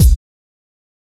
Kick Groovin 7.wav